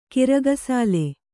♪ kiraga sāle